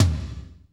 TOM F S L11R.wav